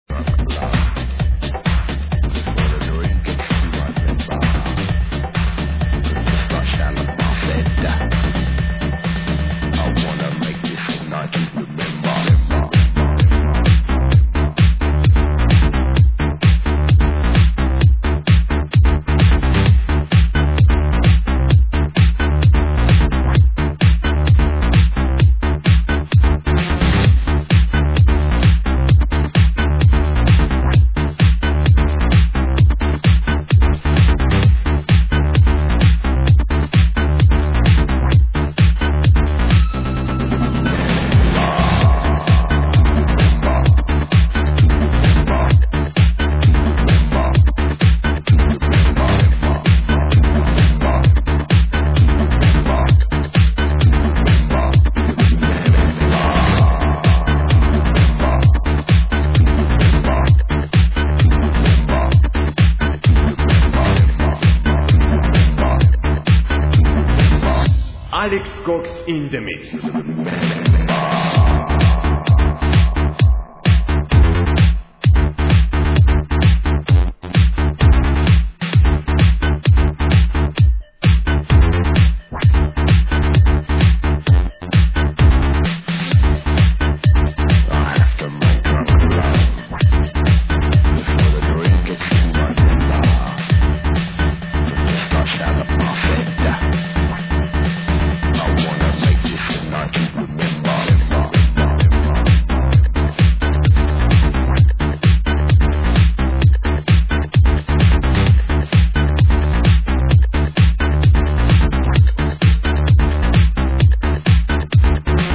[ID] 1 Electro-House track